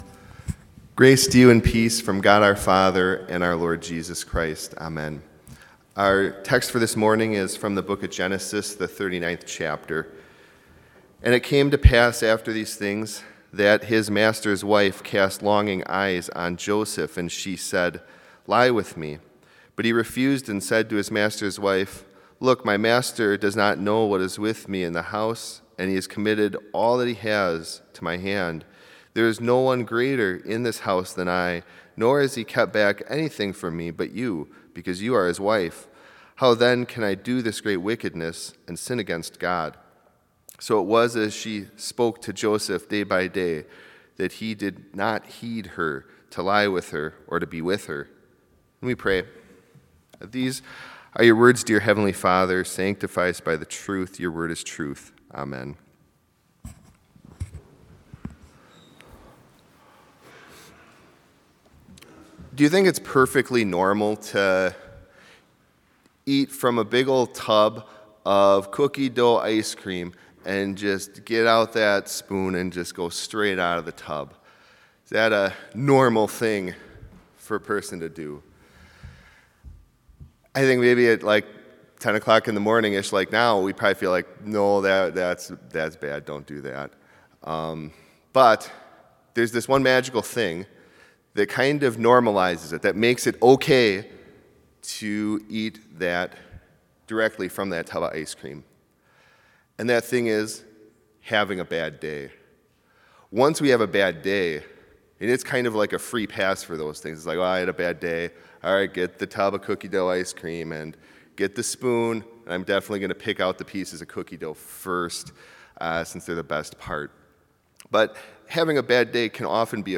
Complete service audio for Chapel - March 17, 2022